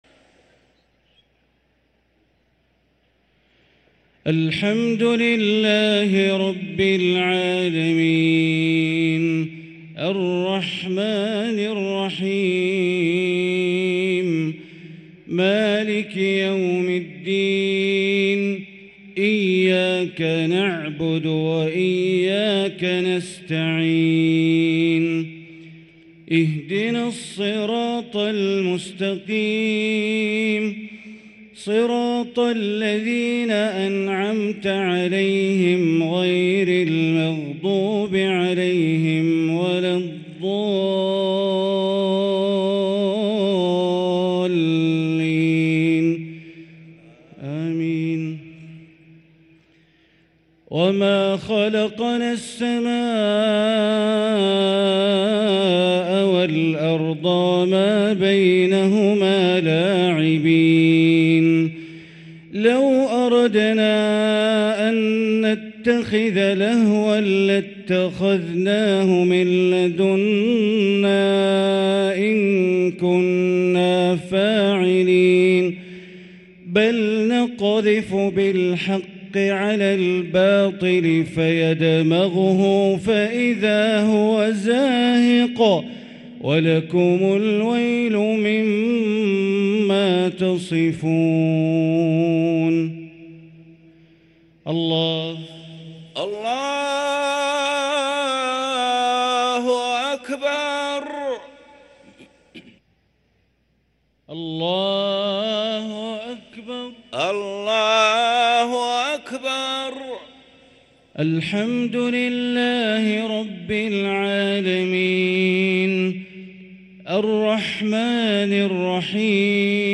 صلاة العشاء للقارئ بندر بليلة 19 رمضان 1444 هـ